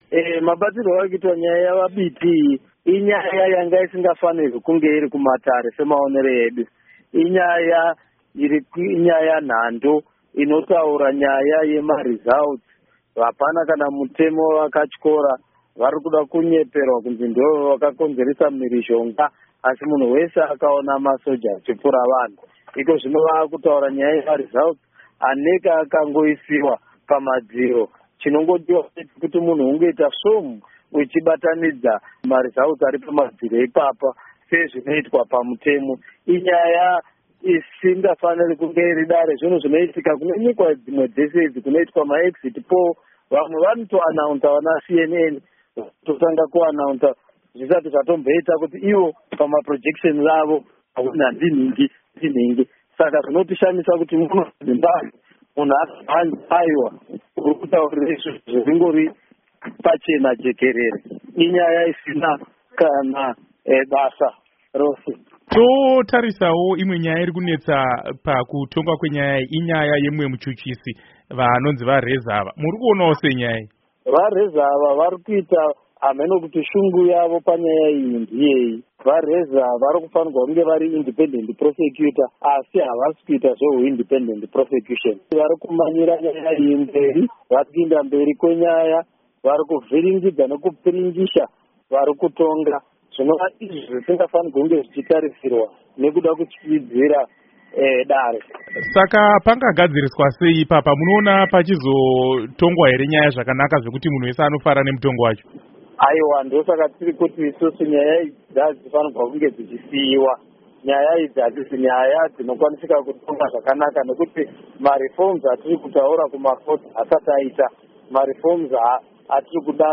Hurukuro naVaTendai Biti